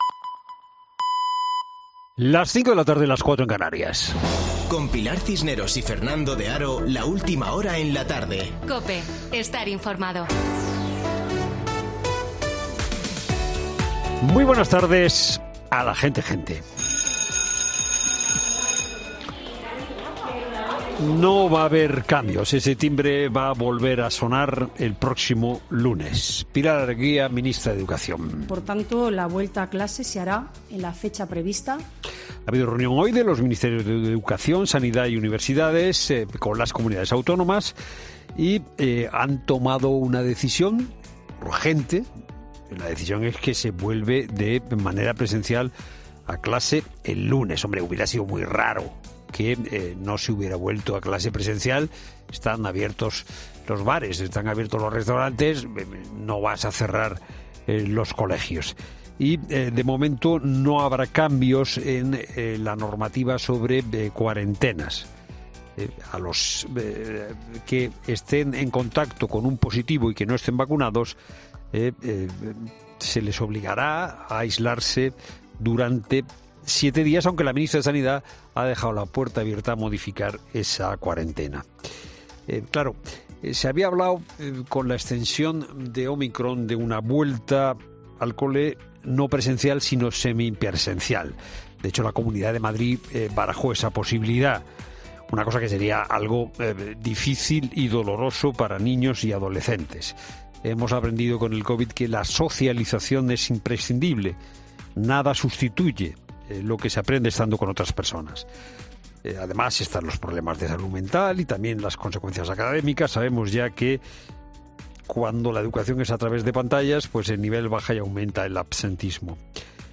Boletín de noticias COPE del 4 de enero de 2022 a las 17:00 horas